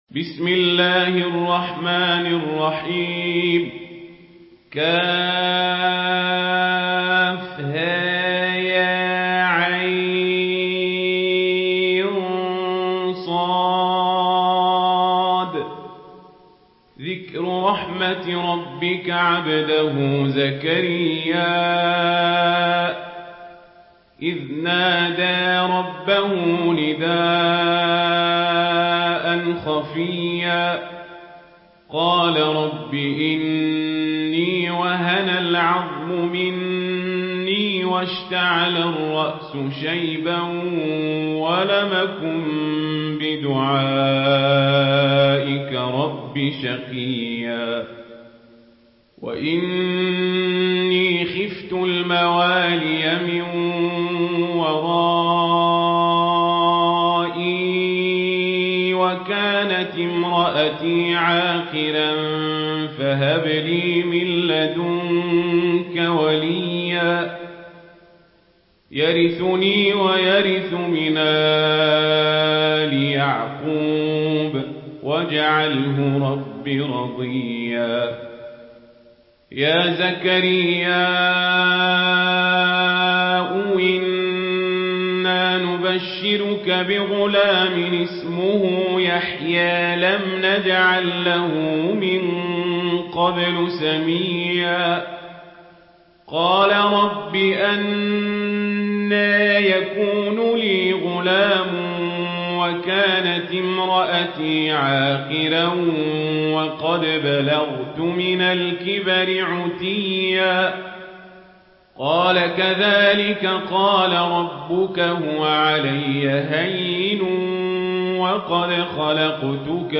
سورة مريم MP3 بصوت عمر القزابري برواية ورش
مرتل